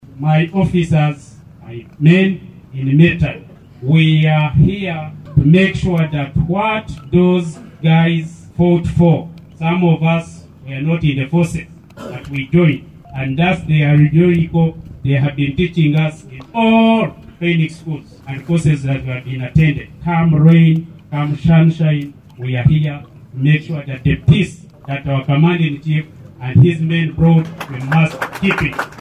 The pledge was made during a ceremony held on February 6th, 2024, at the military headquarters of West Nile in Arua City.